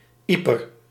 Ypres (/ˈprə/ EE-prə; French: [ipʁ]; Dutch: Ieper [ˈipər]
Nl-Ieper.ogg.mp3